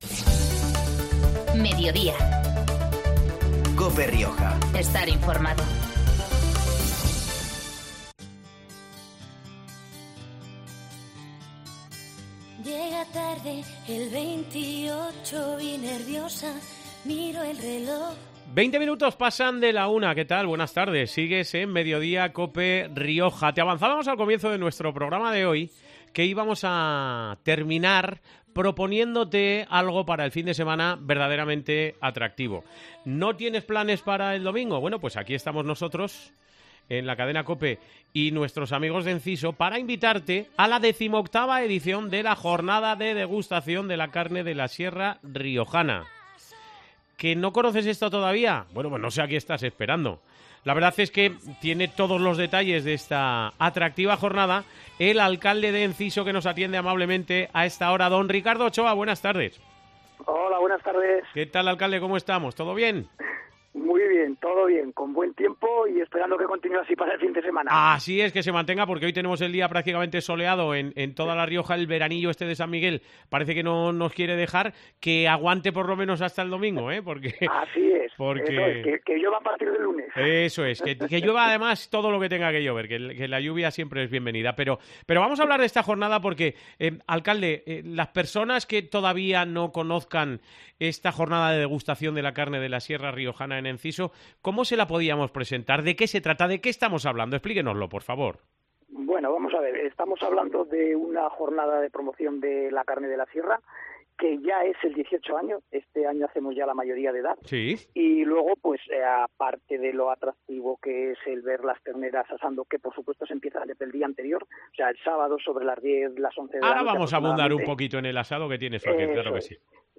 Mediodía Cope Rioja Baja (jueves, 3 octubre 13:20-13:30 horas) Hoy con Ricardo Ochoa, alcalde de Enciso
La jornada se celebrará el domingo pero el sábado por la noche dará comienzo el tradicional asado de las 2 vacas que podrán degustarse el domingo a mediodía. Nos lo contaba hoy el alcalde de Enciso, Ricardo Ochoa.